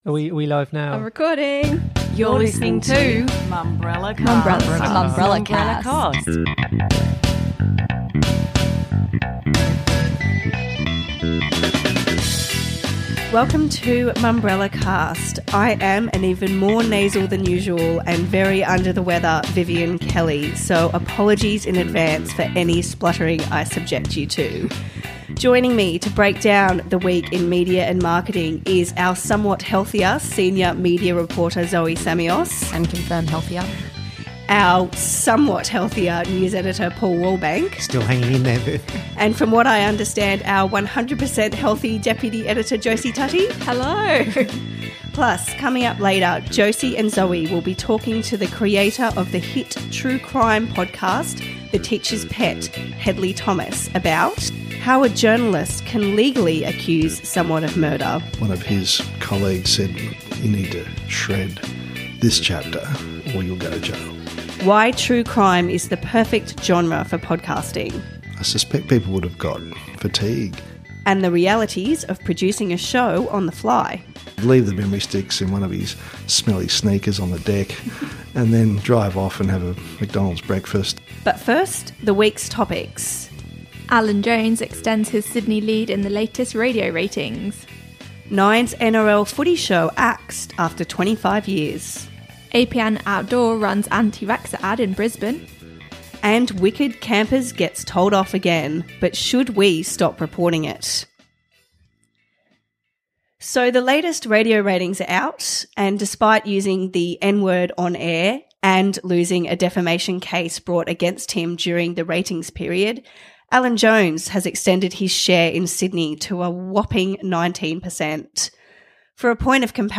Thomas in the Mumbrellacast studio